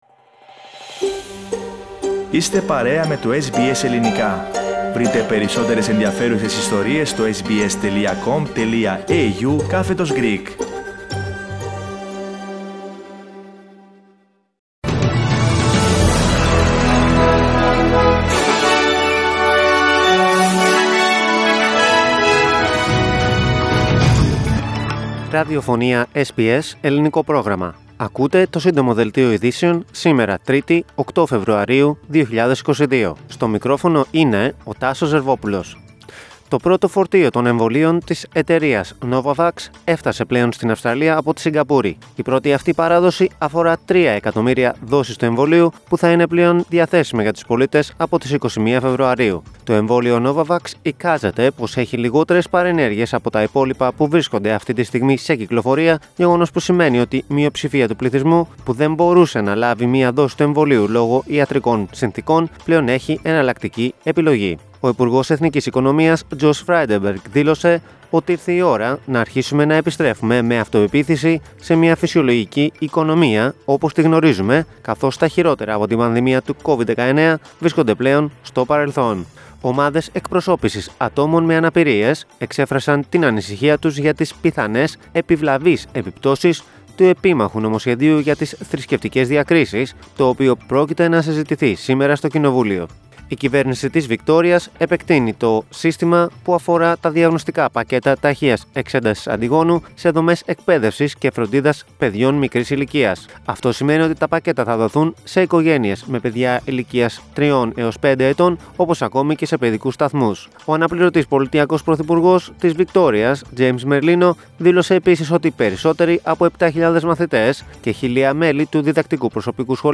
News Flash - Σύντομο Δελτίο